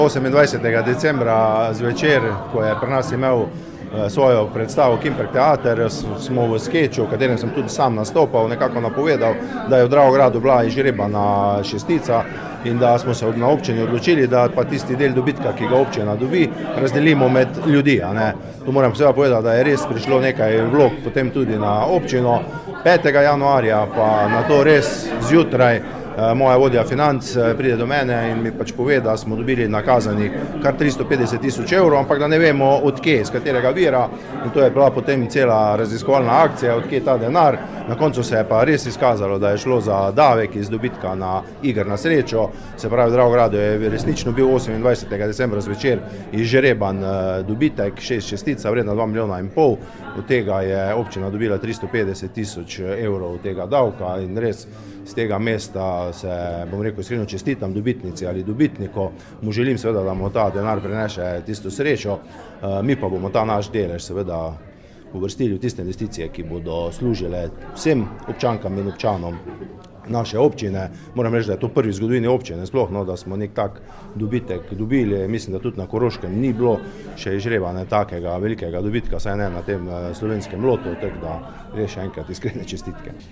Veselo novico vam serviramo: župan Dravograda  Anton Preksavec je v humorističnem vložku 'jasnovidno' napovedal loto dobitek, ki je bil nato res izžreban v Dravogradu:
mp3Preksavec izjava.mp3